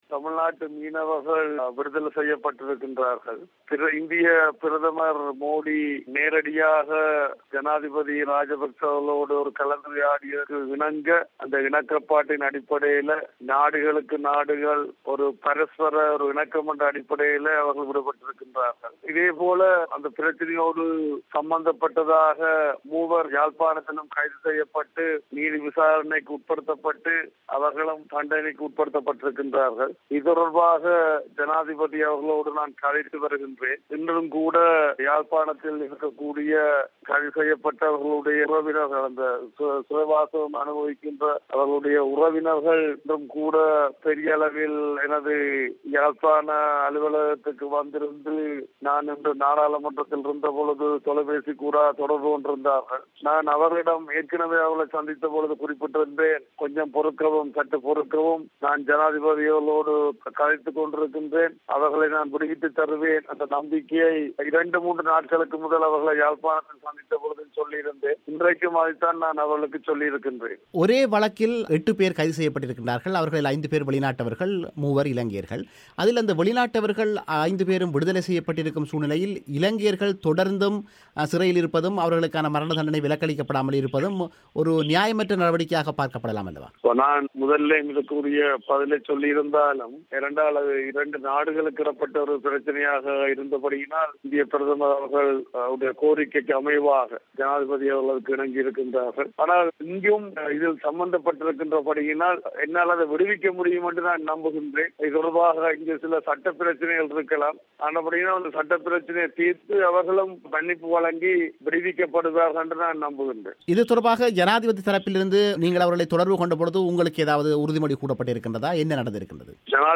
அவர் தமிழோசைக்கு வழங்கிய முழுமையான செவ்வியை இங்கே கேட்கலாம்.